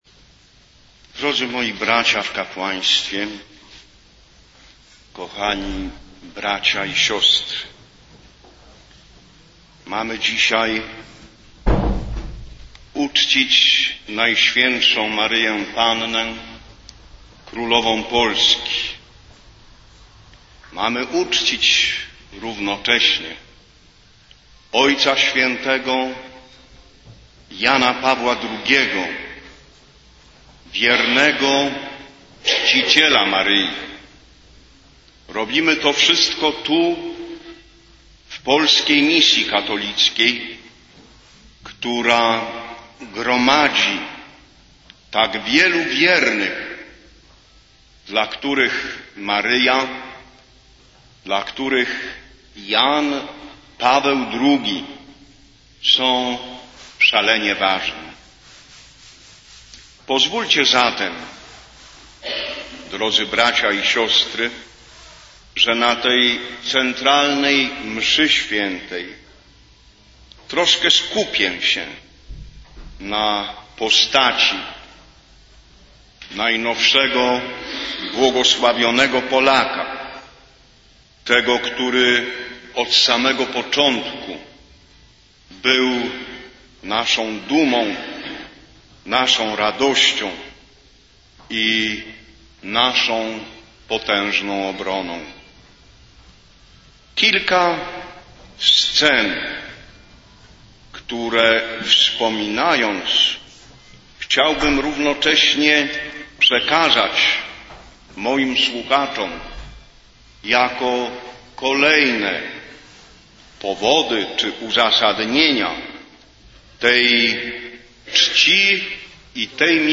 Kazanie odpustowe biskupa Meringa
Kazanie_odpustowe_biskupa_Meringa.mp3